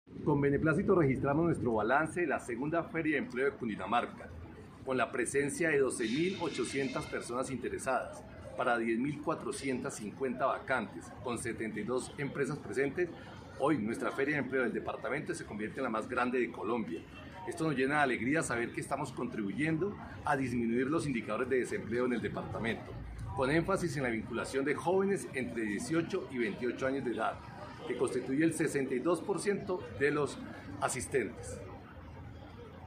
Declaración Oscar Mauricio Núñez Jiménez, director de la Agencia Pública de Empleo.